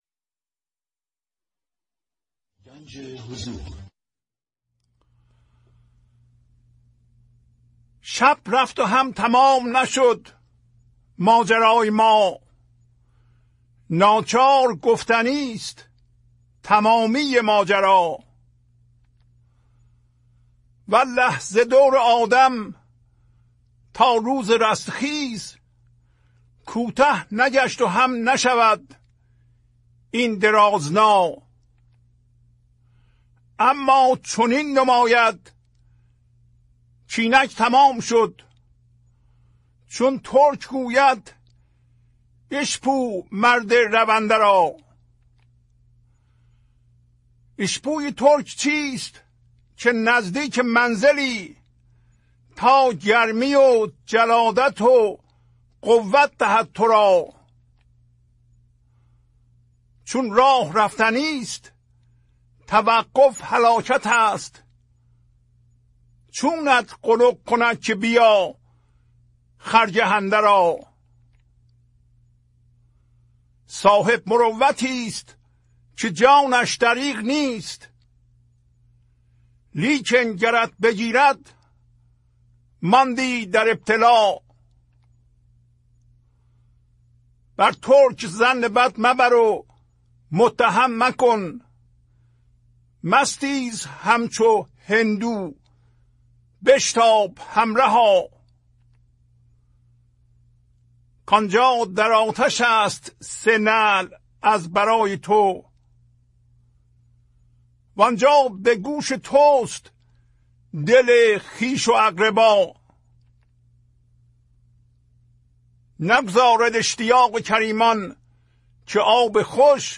خوانش تمام ابیات این برنامه - فایل صوتی
1006-Poems-Voice.mp3